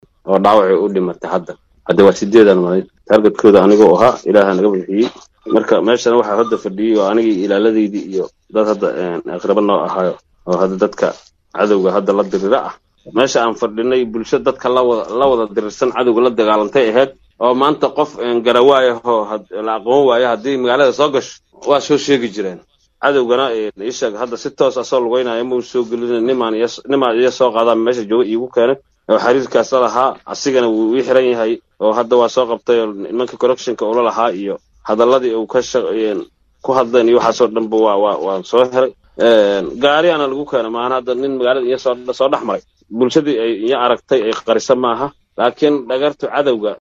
Dhageyso:-Guddoomiye Nuur Dheere oo faahfaahin ka bixiyay siduu kaga badbaaday qaraxii Ismiidaaminta ahaa ee lala beegsaday